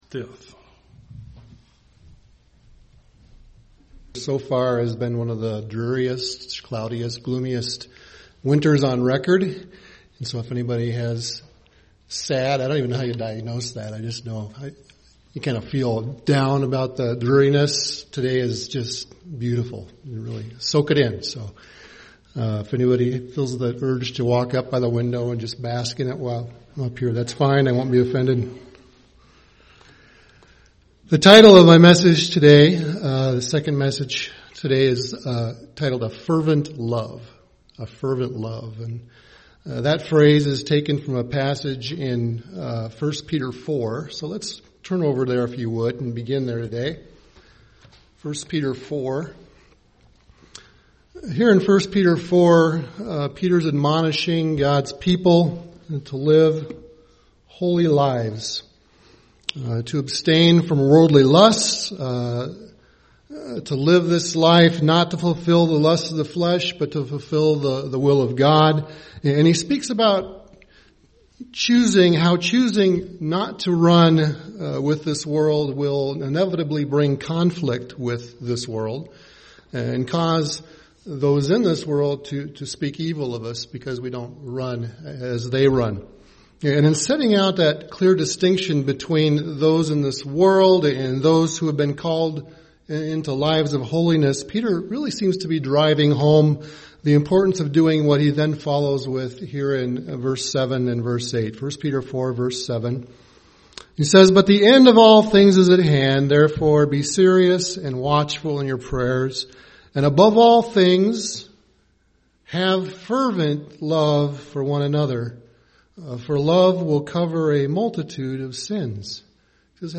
Given in Twin Cities, MN
UCG Sermon fervent love Studying the bible?